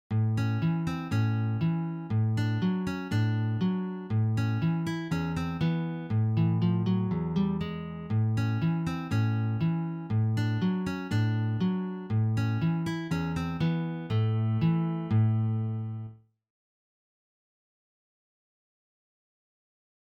Sololiteratur
Gitarre (1)